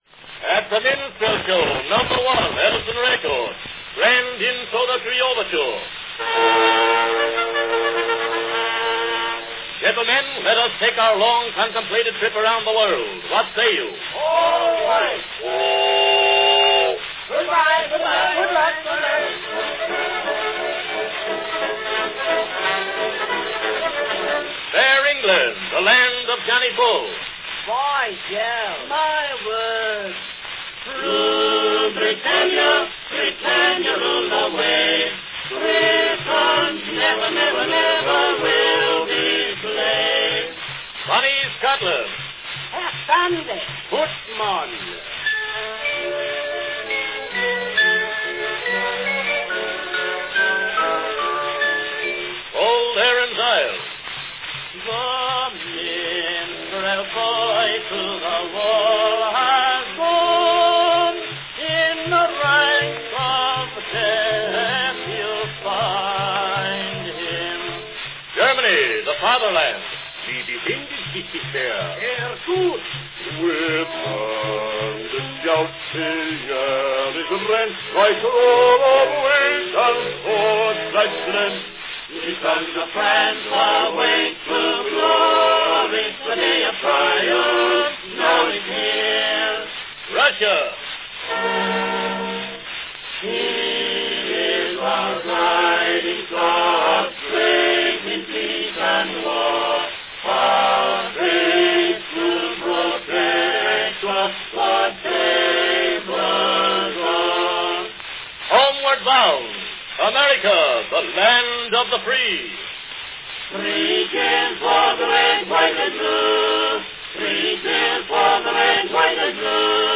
Listen to a complete two-minute wax cylinder recording -- A new cylinder every month.